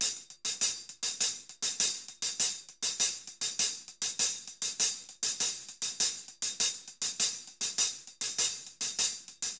HH_Samba 100_2.wav